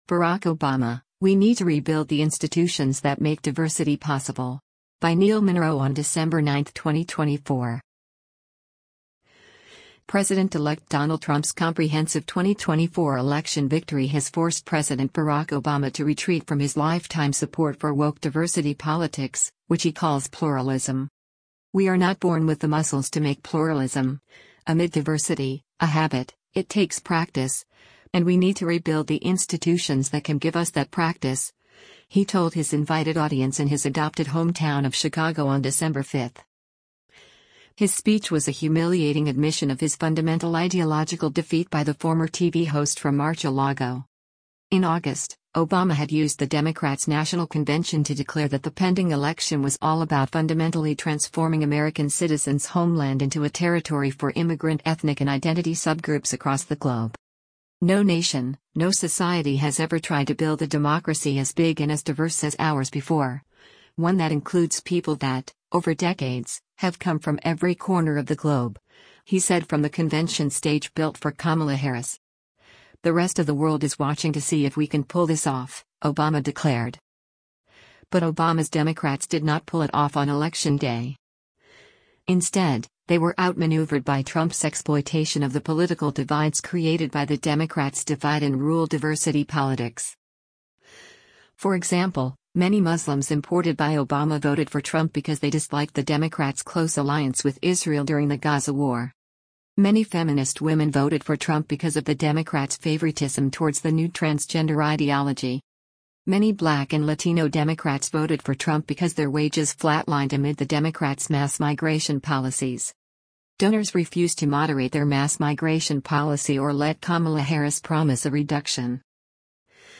CHICAGO, ILLINOIS - DECEMBER 05: Former President Barack Obama addresses the Obama Foundat
“We are not born with the muscles to make pluralism [amid diversity] a habit, it takes practice, and we need to rebuild the institutions that can give us that practice,” he told his invited audience in his adopted hometown of Chicago on December 5.